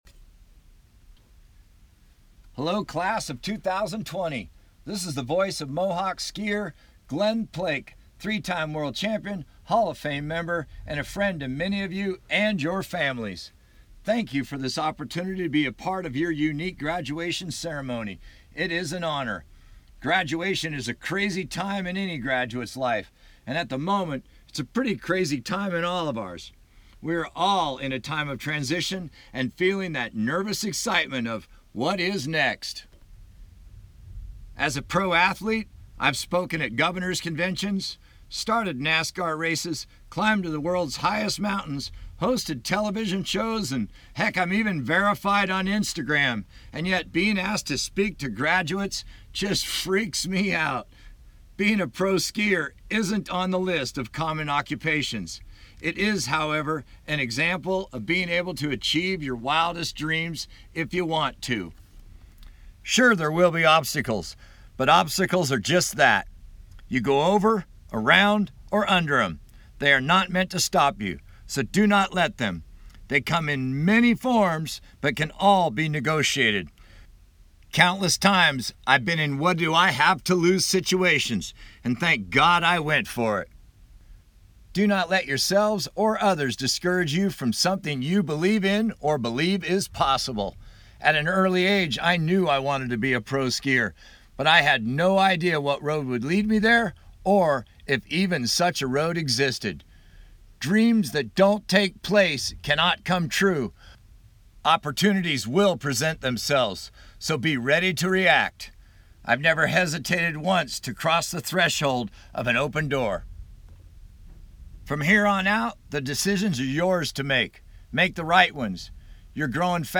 Keynote Speaker Glen Plake, a professional skier recorded a message to the graduates that was played on the live radio broadcast during the drive by ceremony.
Keynote_Address-Glen_Plake.mp3